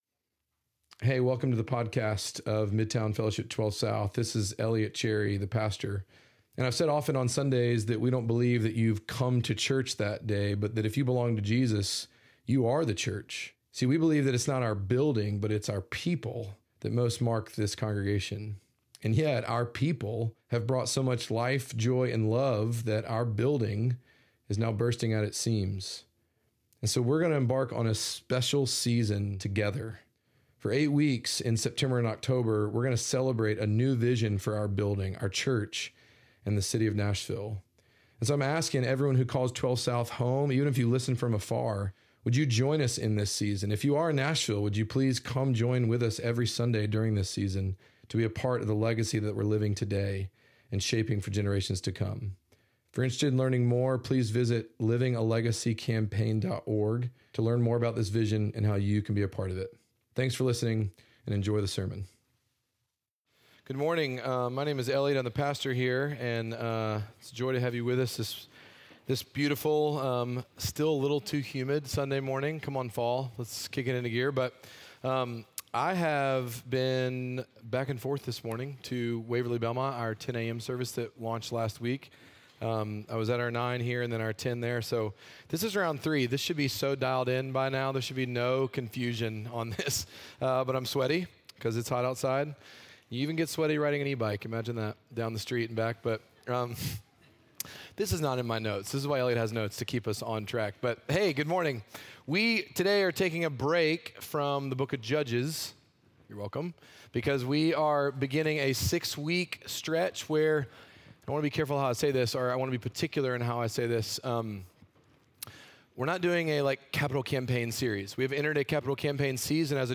Midtown Fellowship 12 South Sermons The Need Sep 22 2024 | 00:44:23 1x 00:00 / 00:44:23 Subscribe Share Apple Podcasts Spotify Overcast RSS Feed Share Link Embed